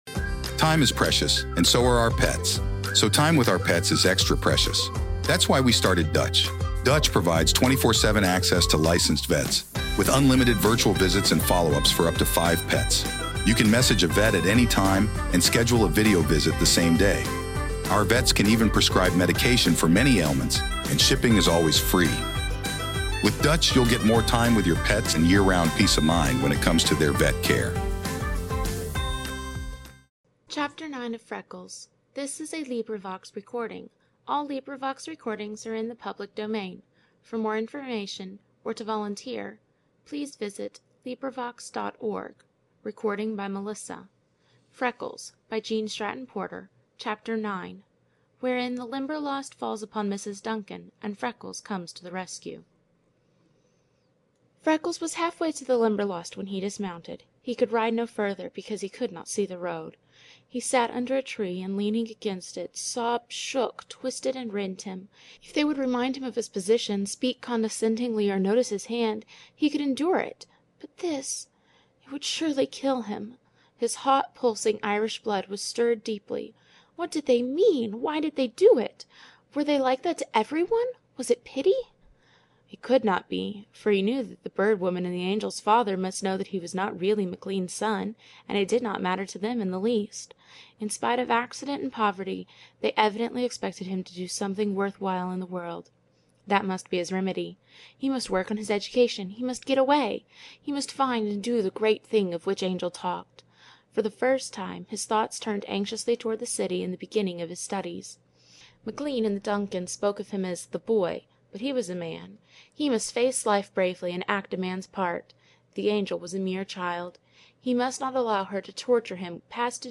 This is a collaborative reading.